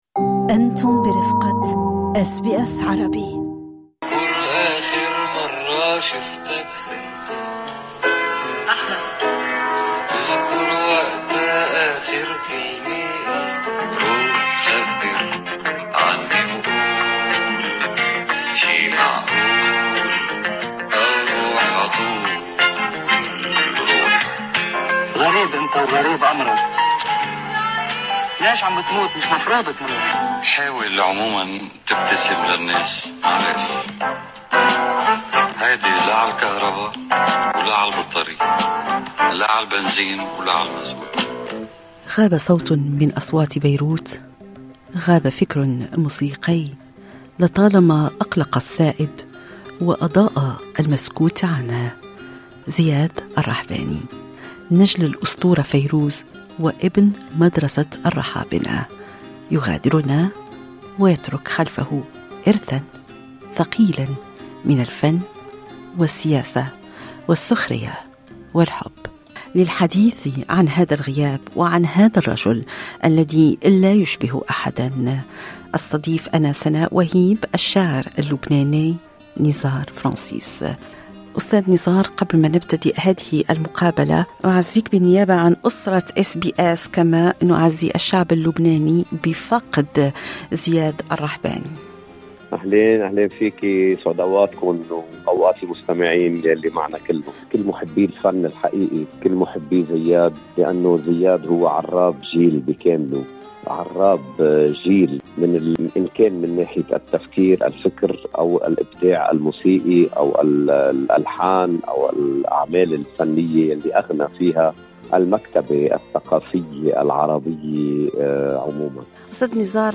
هو ابن فيروز والرحابنة، لكنه اختار لنفسه طريقًا خاصًّا لا يشبه أحدًا. في هذه المقابلة، يتحدث الشاعر الكبير نزار فرنسيس عن رحيل زياد بكلمات مؤثرة، مؤكّدًا أن زياد سيبقى حاضرًا في مفردات الناس، وفي تفاصيل يومهم العادي، حتى بعد الغياب.